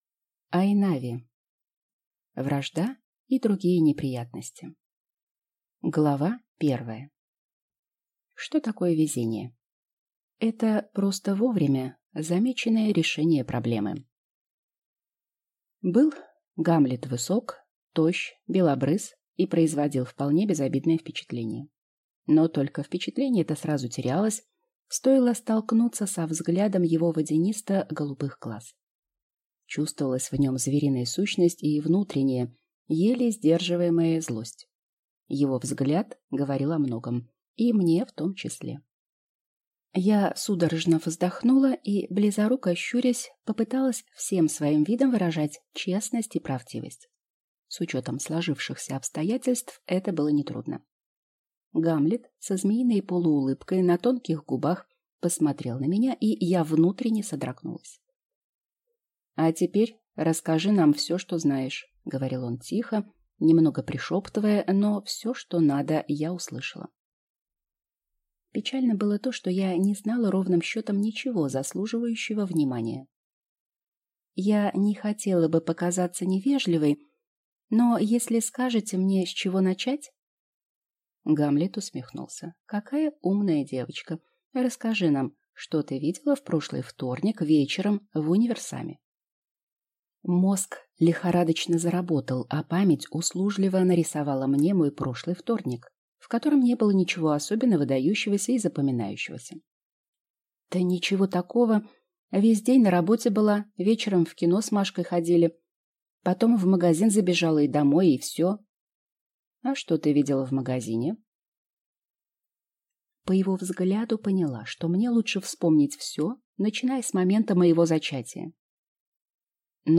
Аудиокнига «Вражда» и другие неприятности | Библиотека аудиокниг